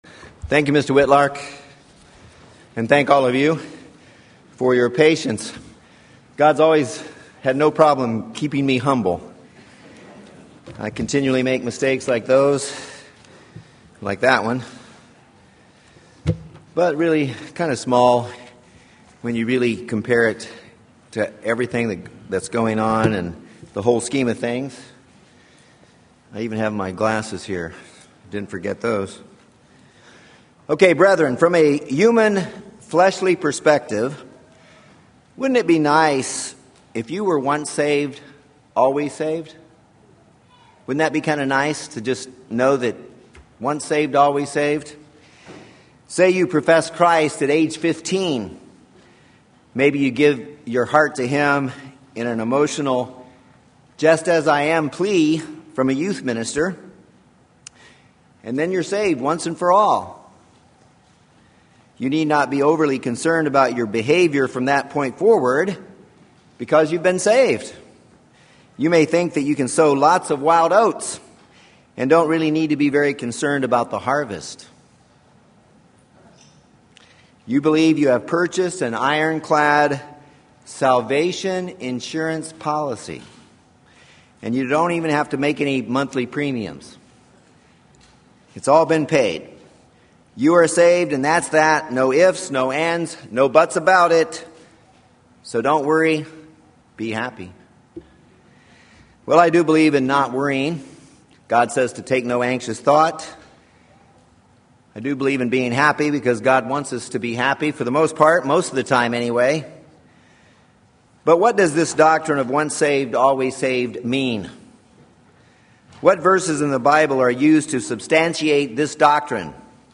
Is it possible to “fall away” or to lose out on salvation? Look for answers to these important questions in this sermon and be ready to give an answer on this subject yourself.